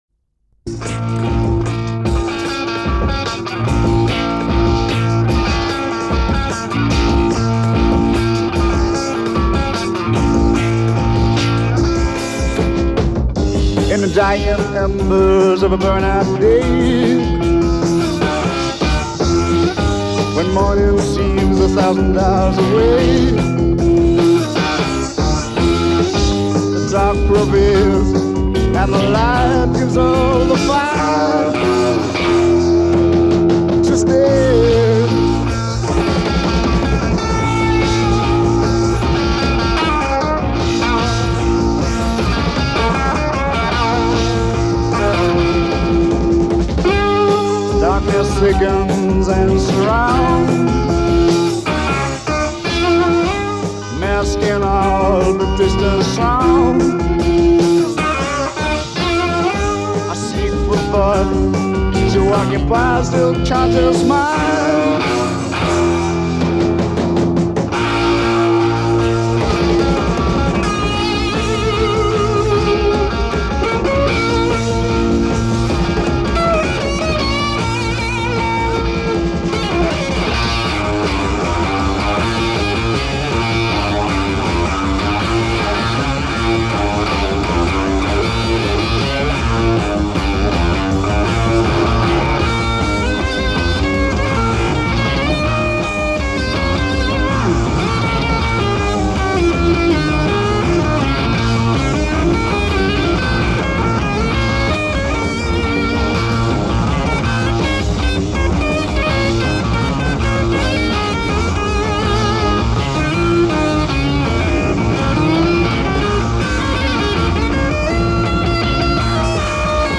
the epitome of Heavy distortion-drenched Rock/Prog